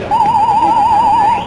pithorn.wav